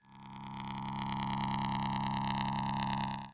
ihob/Assets/Extensions/RetroGamesSoundFX/Hum/Hum10.wav at master
Hum10.wav